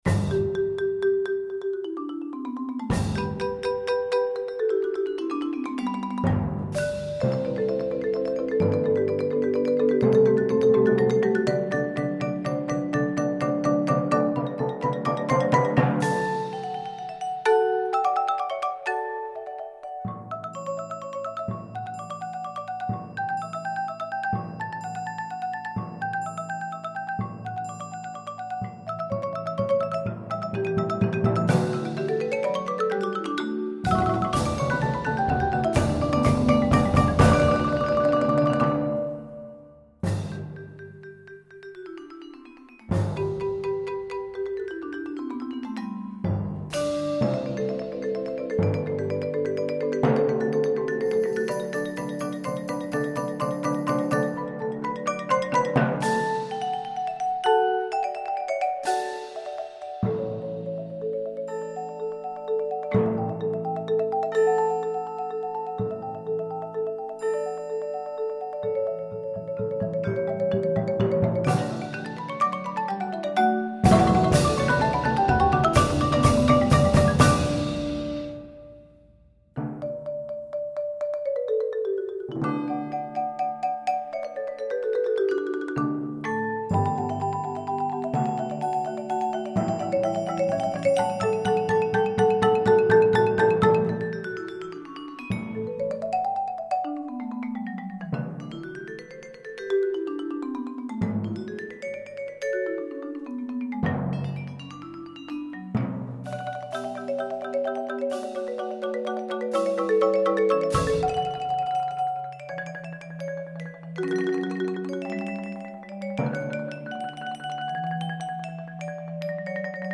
Voicing: 7-8 Percussion